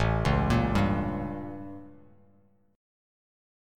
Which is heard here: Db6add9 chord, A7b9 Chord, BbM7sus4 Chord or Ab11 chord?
A7b9 Chord